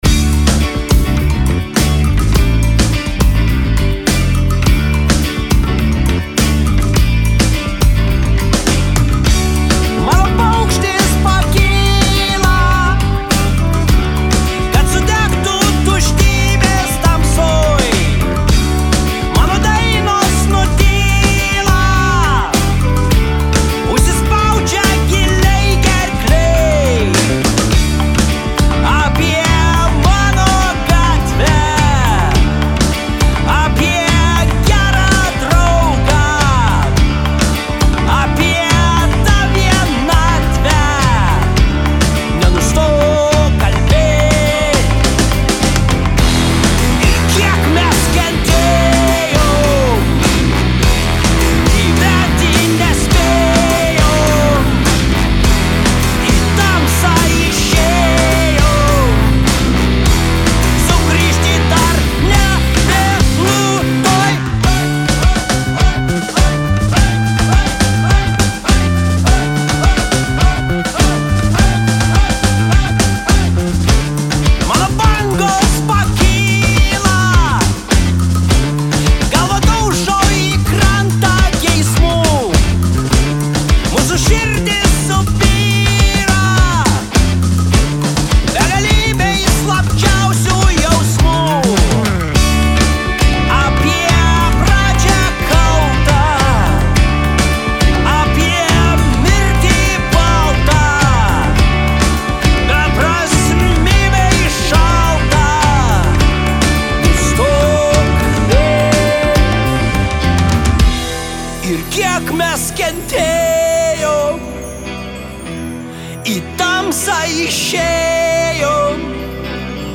itin melodingas ir lyriškas.